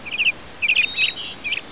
Canary.wav